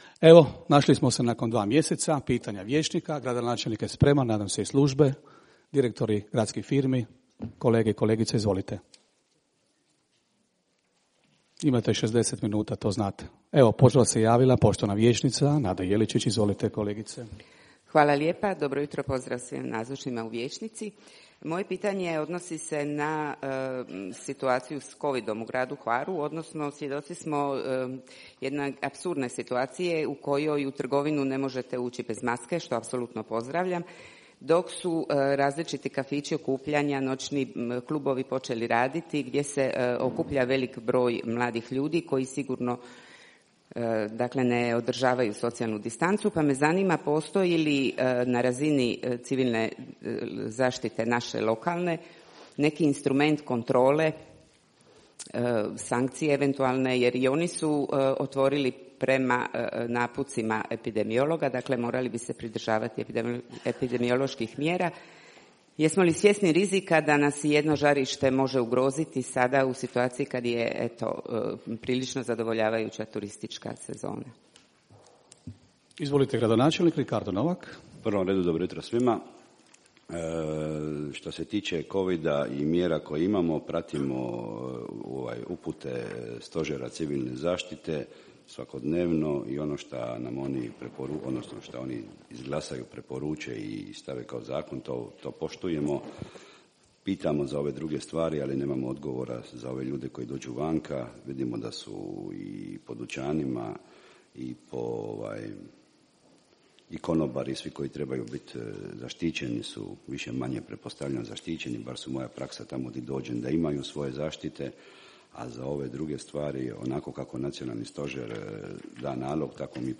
Snimke 45. sjednice Gradskog vijeća Grada Hvara